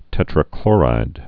(tĕtrə-klôrīd)